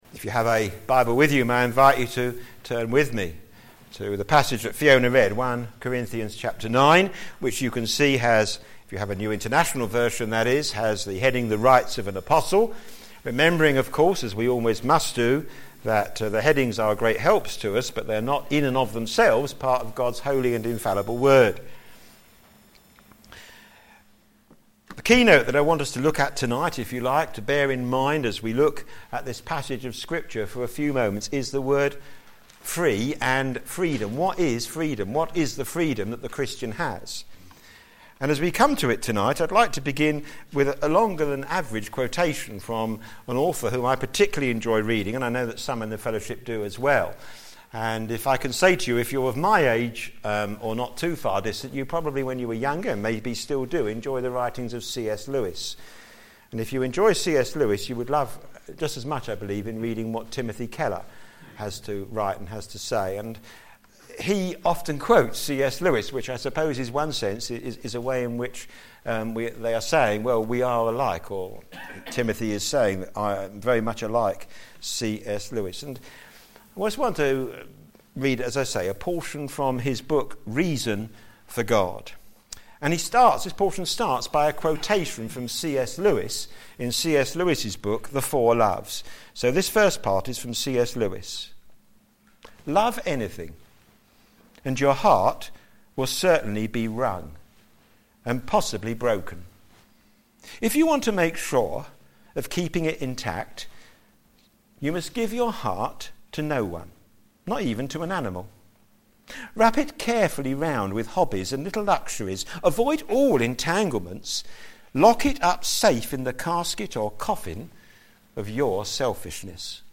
Media Library Media for p.m. Service on Sun 19th Apr 2015 18:30 Speaker
Sermon In the search box below